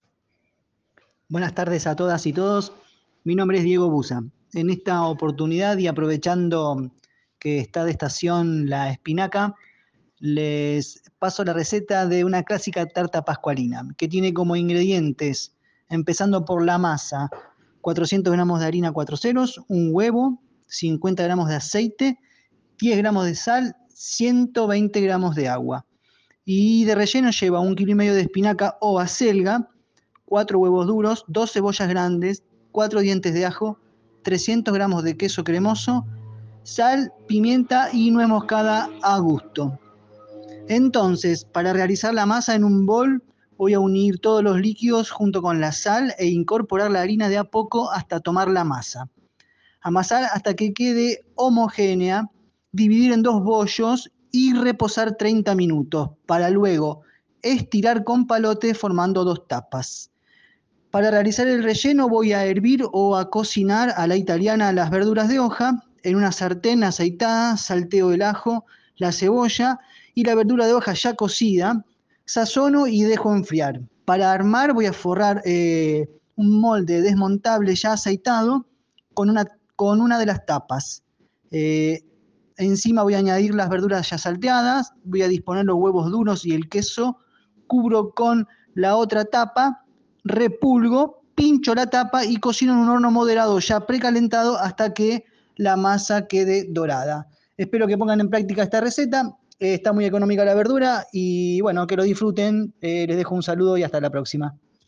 Audio receta de la tarta de espinaca